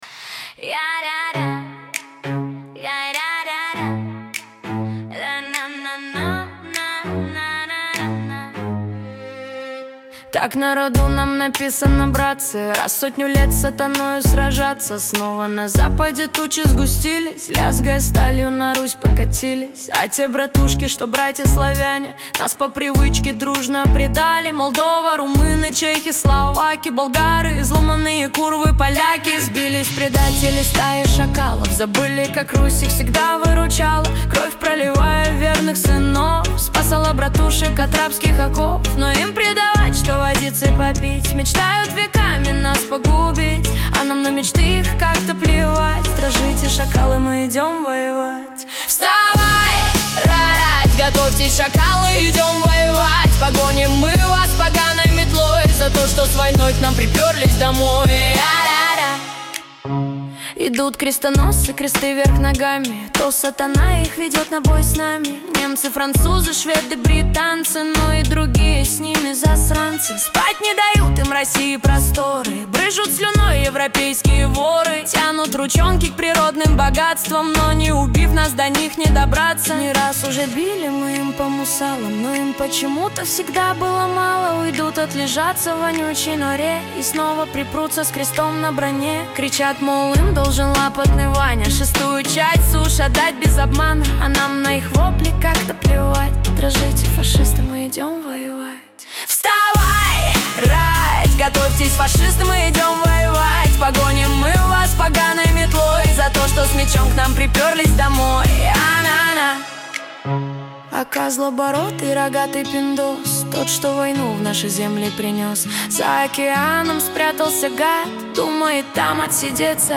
Качество: 320 kbps, stereo
Военный трек